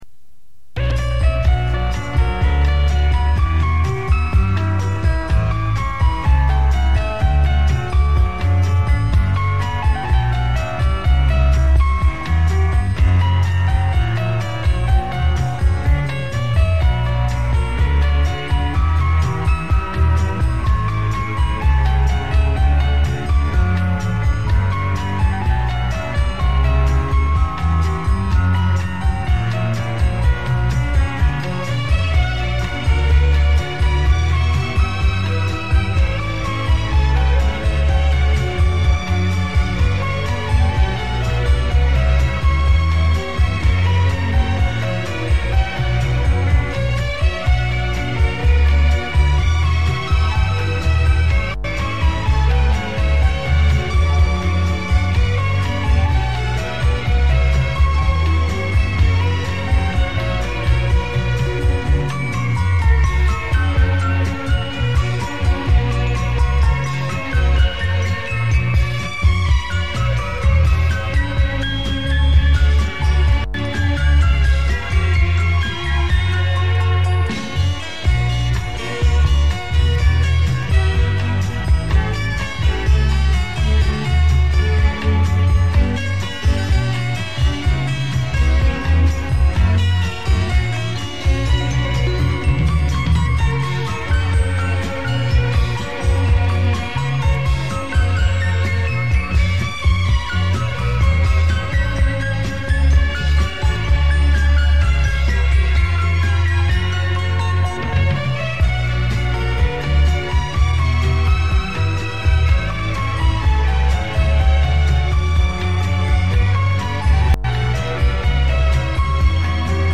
Очень прошу, угадайте пожалуйста несколько инструментальных мелодий.